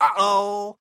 Звуки ой
Звук мужского о-оу